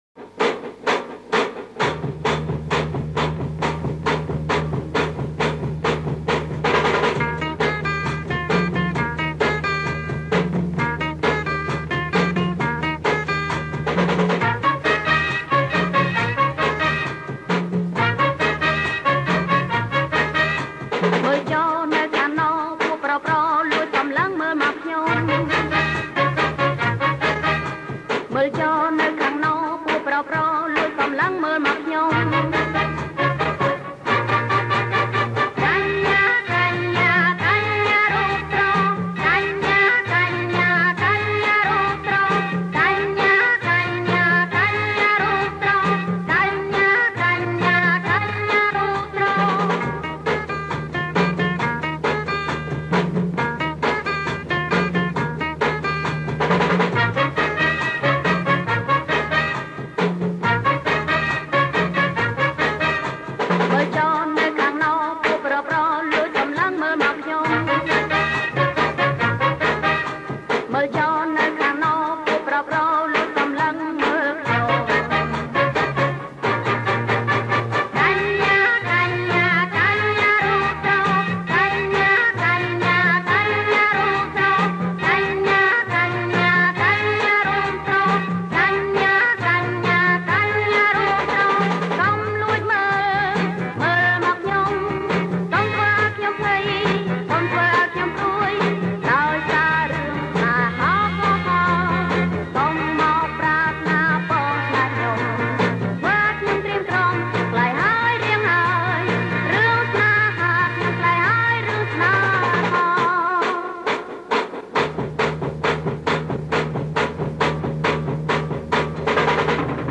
• ប្រគំជាចង្វាក់ Jerk